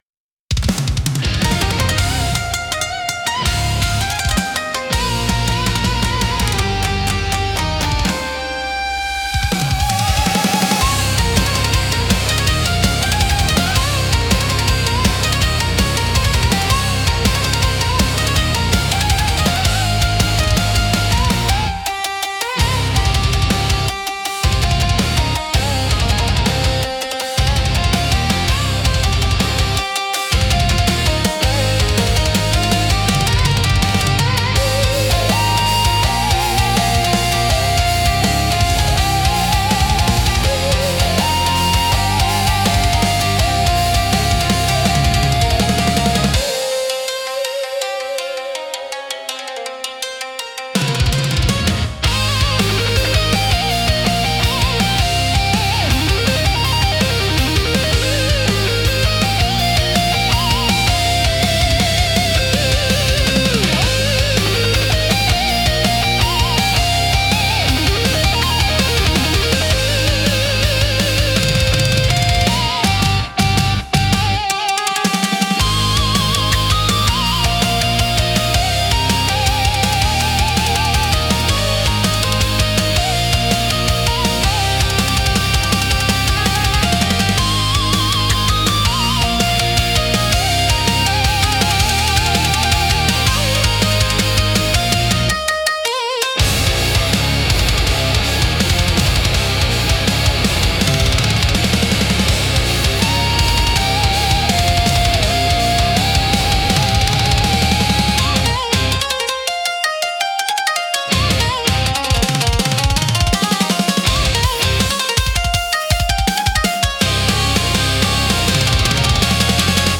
尺八の幽玄な響きと琴の繊細な調べが、重厚なギタートーンや高速ビートと絡み合い、独自の緊張感とダイナミズムを生み出します。
聴く人に力強さと神秘性を同時に感じさせ、日本古来の精神と現代のエネルギーを融合したインパクトを与えます。